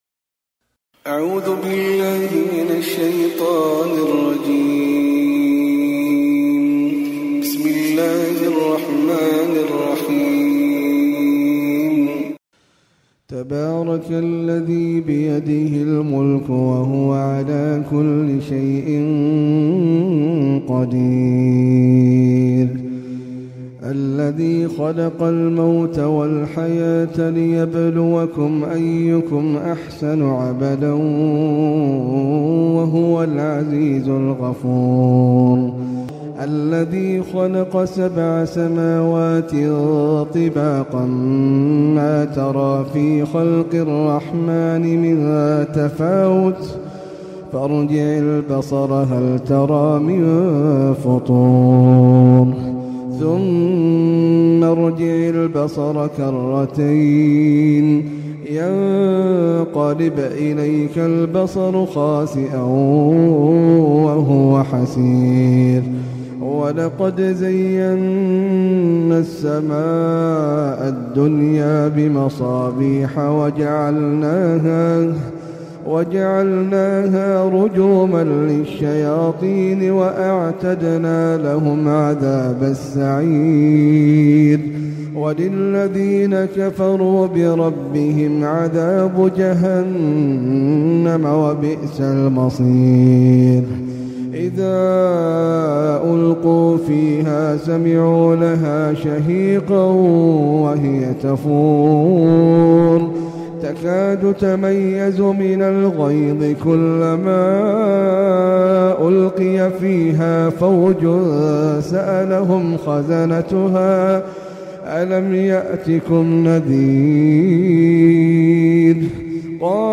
Sûrat Al-Mulk (Dominion) - Al-Mus'haf Al-Murattal (Narrated by Hafs from 'Aasem)
Audio - high quality